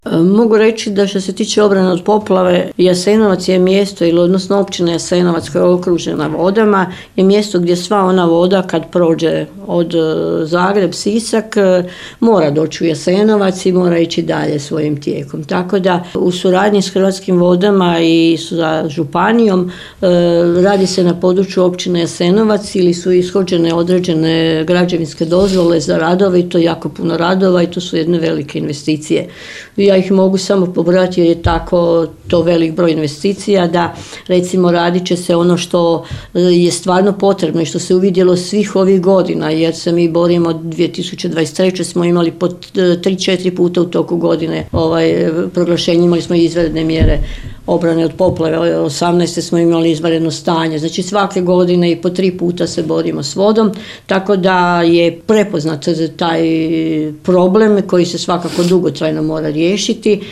O tome što se trenutno radi kako bi se osiguralo područje Općine Jasenovac od eventualnih nadolazećih visokih vodostaja govori načelnica Marija Mačković